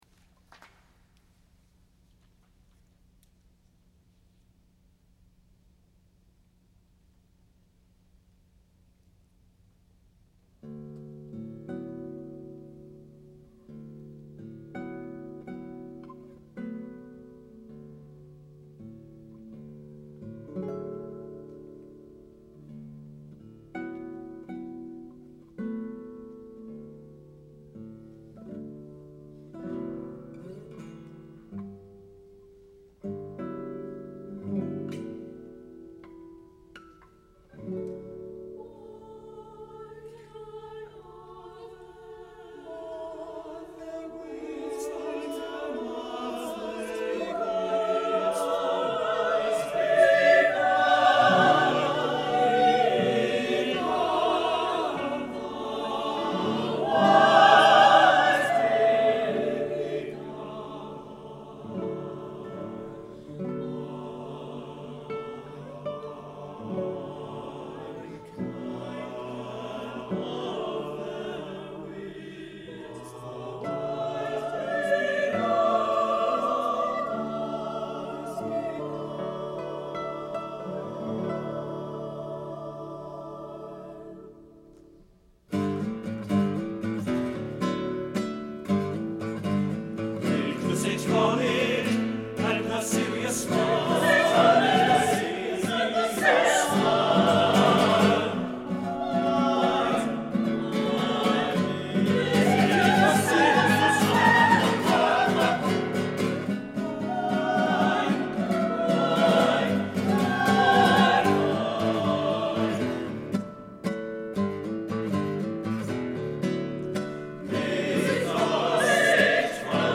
SATB divisi & Guitar